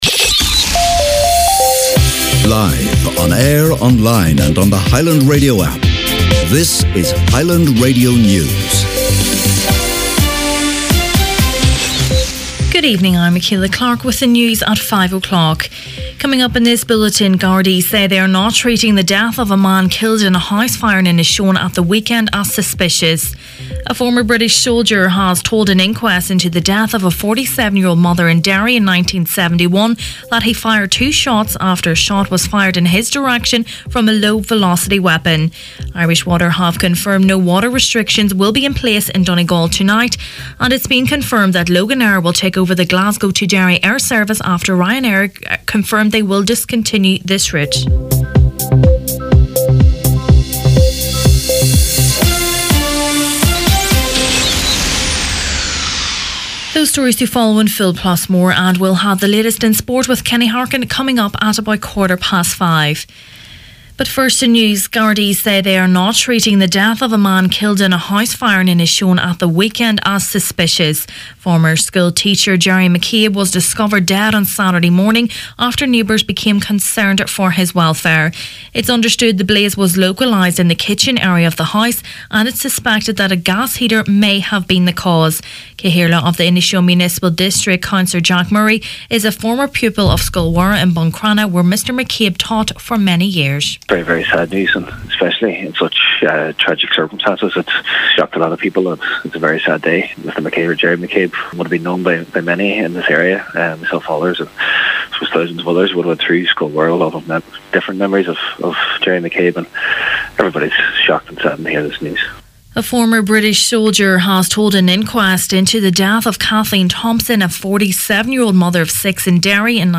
Main Evening News, Sport and Obituaries Tuesday 6th March